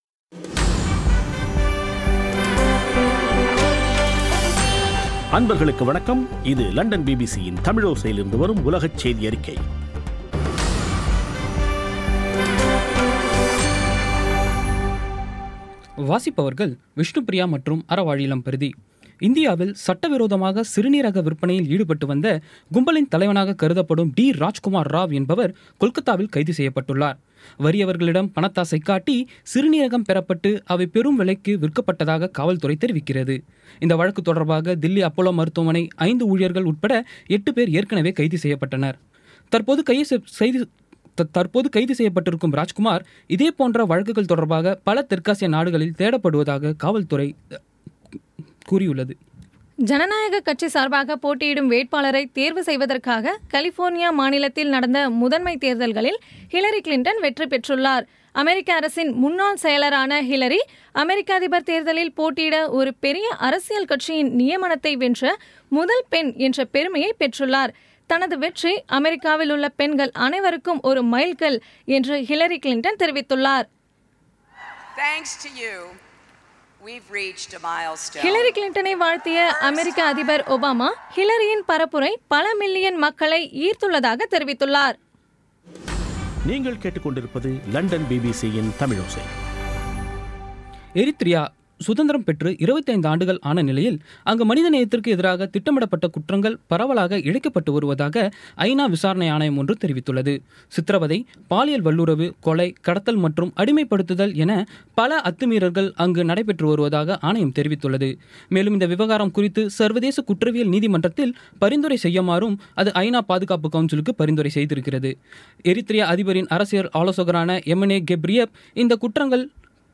இன்றைய (ஜூன் 8ம் தேதி ) பிபிசி தமிழோசை செய்தியறிக்கை